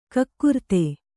♪ kakkurte